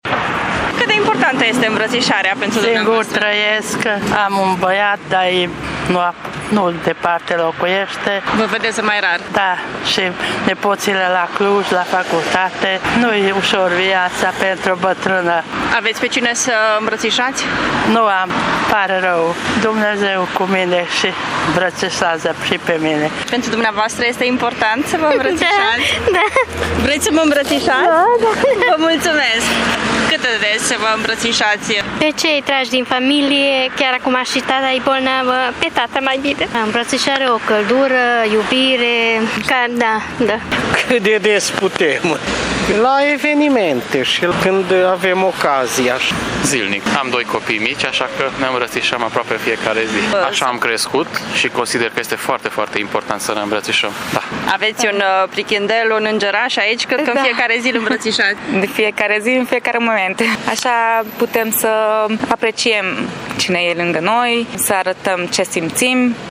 Târgumureșenii recunosc că îmbrățișarea este prezentă zilnic în viața lor, mai ales dacă au copii mici, însă și adulții simt nevoia să-i îmbrățișeze chiar și pe … necunoscuți: